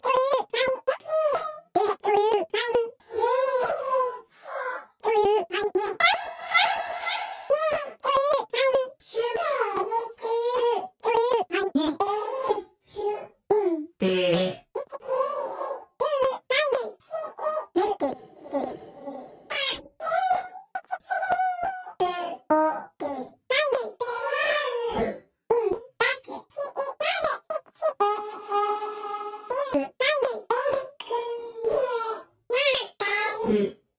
今回は 120 BPM (四分音符 1 つにつき 0.5 秒) でやることとしたので、8 で割って音の長さを調整しています。
いろいろ狂っておりますが、とりあえずできました。(最初のほうは頑張って修正したので割とピッチが合っているのですが、途中から諦めたのでピッチが狂い気味です。